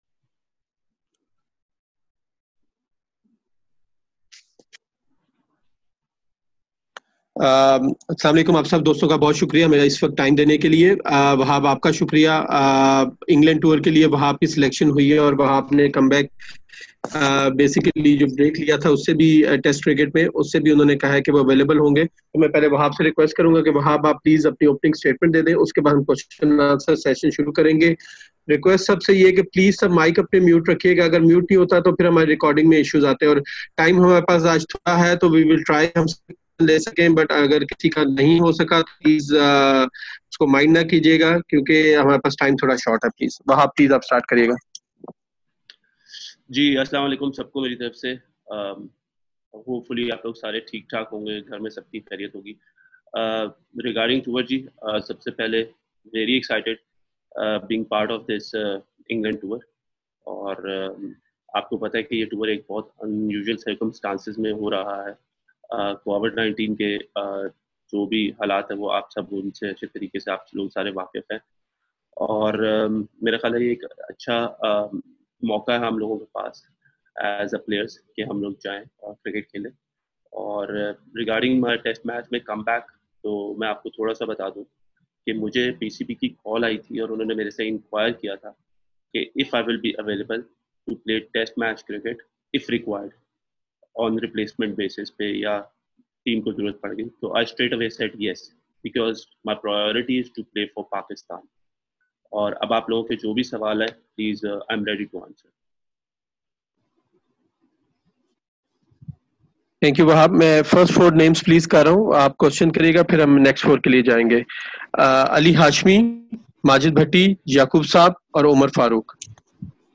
Fast Bowler Wahab Riaz speaks with media